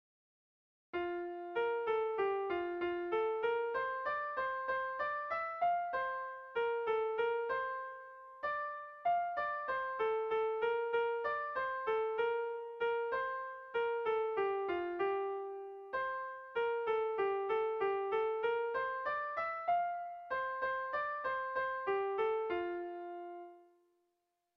Dantzakoa
Abadiño < Durangaldea < Bizkaia < Euskal Herria
ABD